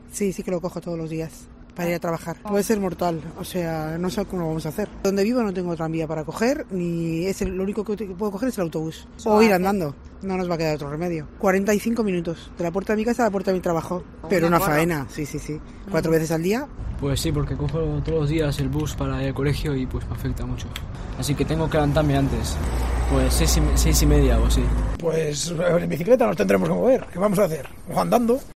COPE Euskadi recoge reacciones de los vitorianos afectados por la huelga indefinida de Tuvisa